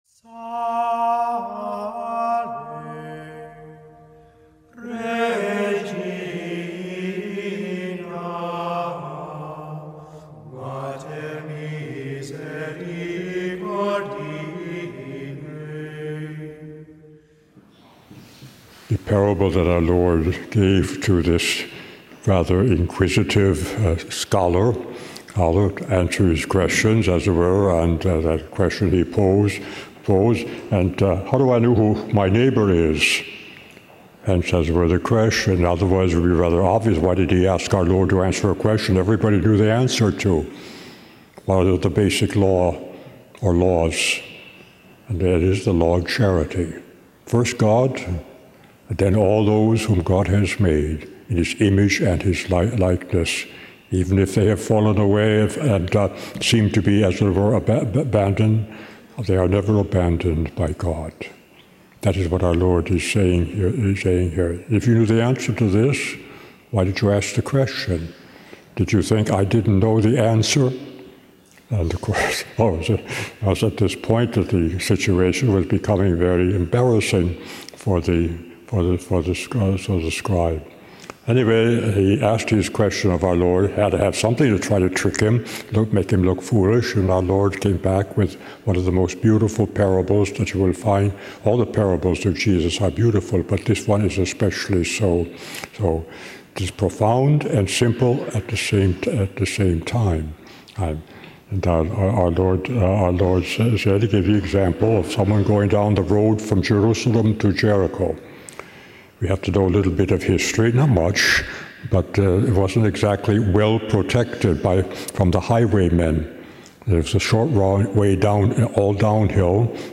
Homily
Mass: 15th Sunday in Ordinary Time - Sunday Readings: 1st: deu 30:10-14 Resp: psa 69:14, 17, 30-31, 33-34, 36-37 2nd: col 1:15-20 Gsp: luk 10:25-37 Audio (MP3)